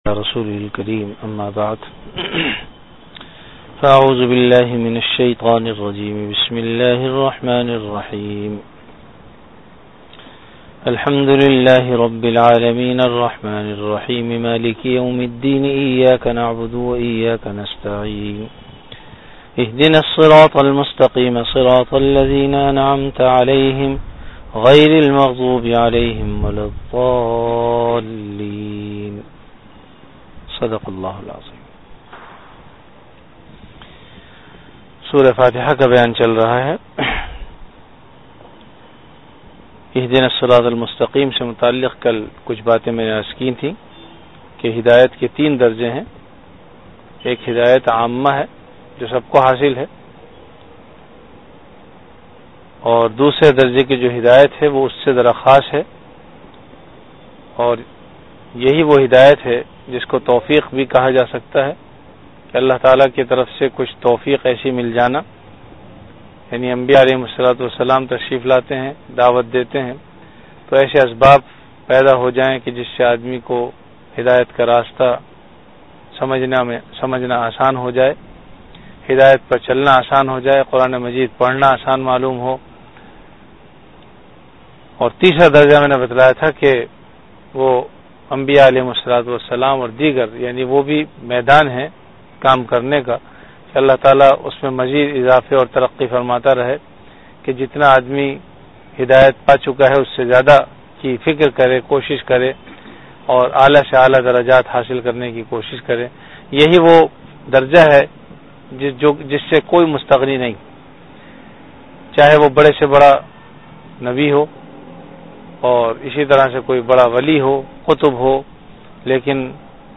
Dars-e-quran · Jamia Masjid Bait-ul-Mukkaram, Karachi